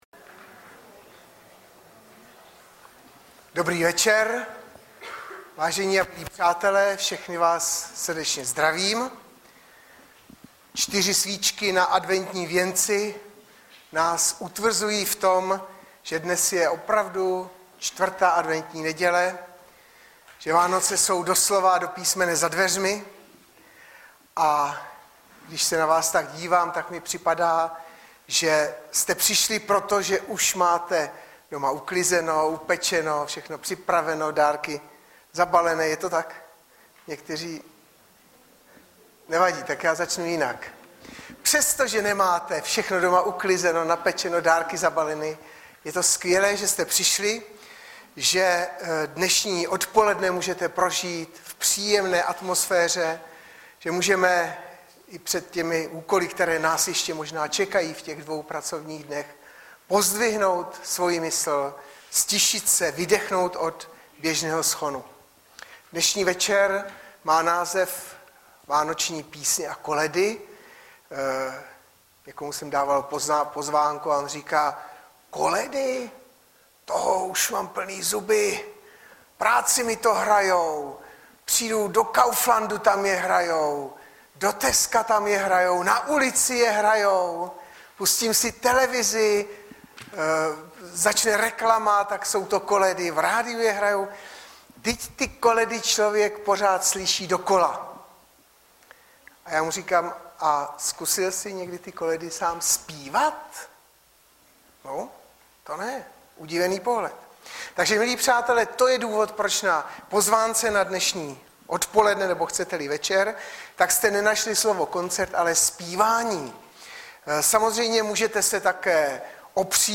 Webové stránky Sboru Bratrské jednoty v Litoměřicích.
21.12.2014 - VÁNOČNÍ PÍSNĚ A KOLEDY